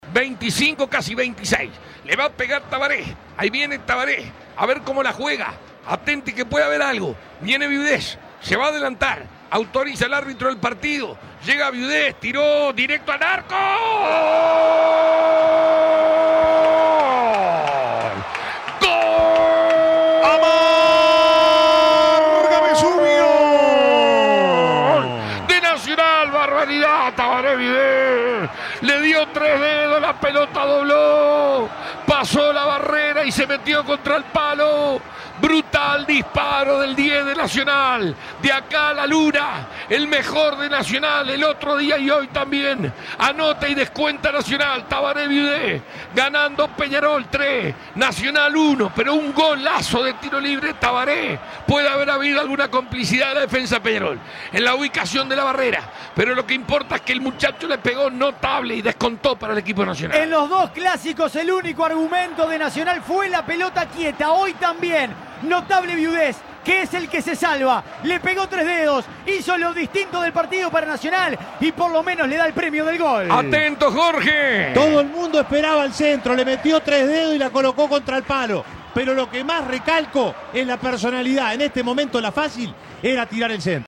Escuchá los goles relatados por Alberto Sonsol.